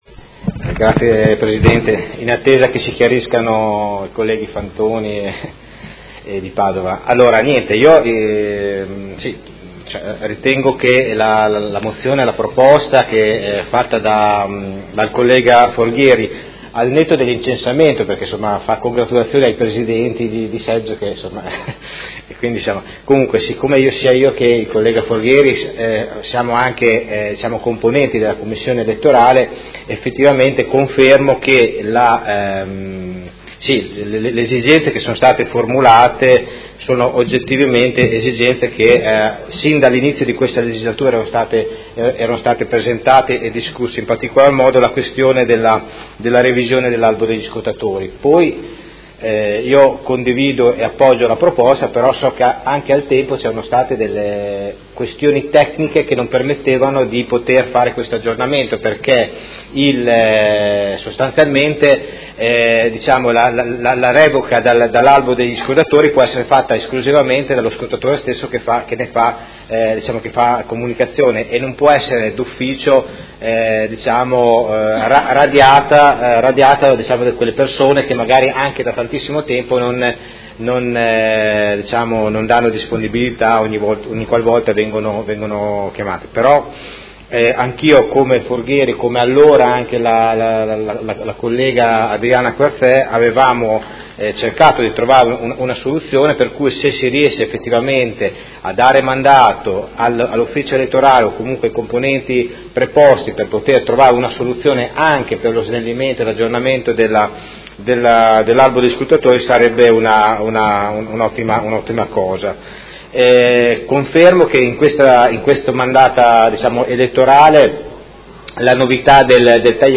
Seduta del 26/04/2018 Mozione presentata dai Consiglieri Forghieri, Morini, Poggi, Di Padova, Pacchioni, De Lillo, Fasano, Lenzini, Liotti e Venturelli (PD) avente per oggetto: Valutazioni sull’andamento delle operazioni di voto e proposte migliorative in vista delle prossime elezioni